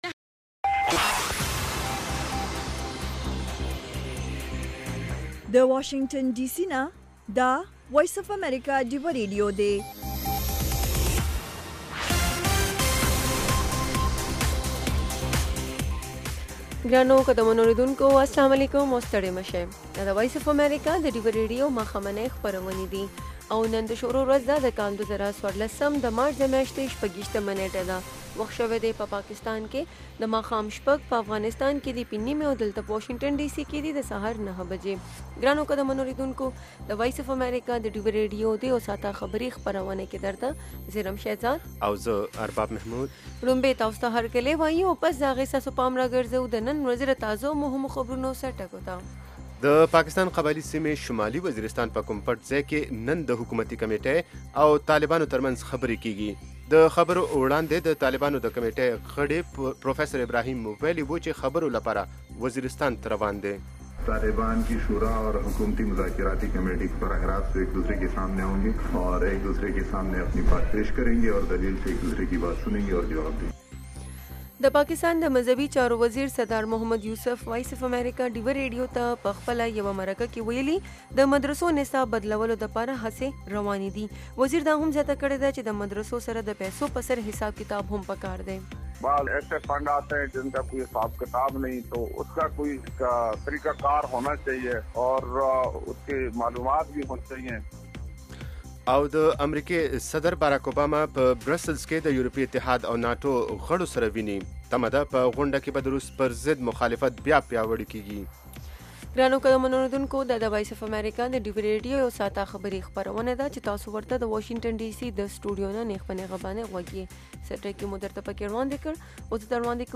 خبرونه - 1300
د وی او اې ډيوه راډيو ماښامنۍ خبرونه چالان کړئ اؤ د ورځې د مهمو تازه خبرونو سرليکونه واورئ.